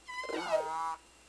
Southern Resident Call S - 1
The four OVAL array hydrophone locations are noted with small circles.
The vocalizing orca is at the location and depth shown by the straight line from our local reference point.